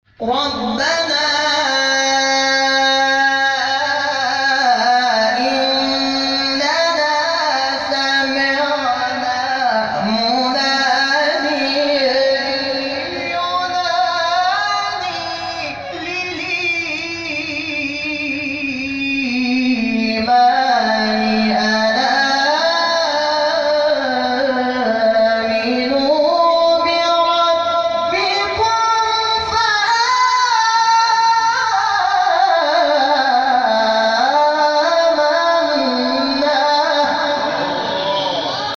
حساسیت بر آموزش ترکیبات لحنی در جلسه هفتگی بیت‌القرآن